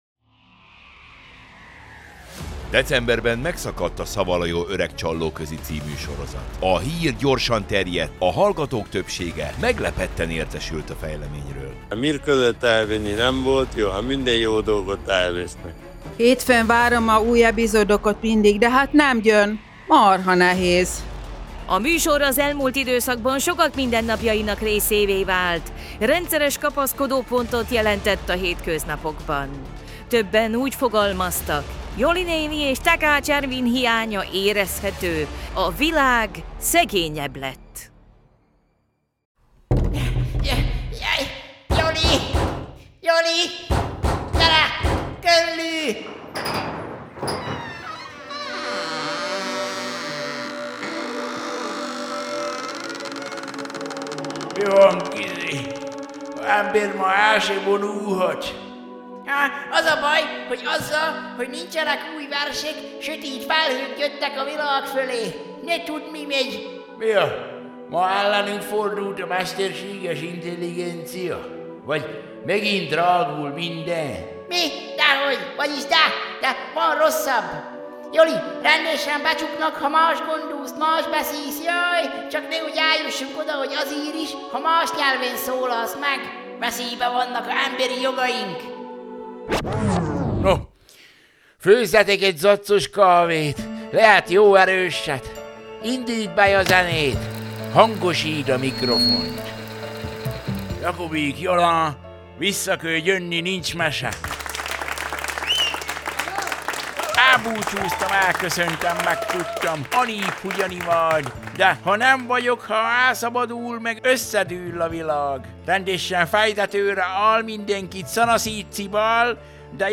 Szaval a jó öreg csallóközi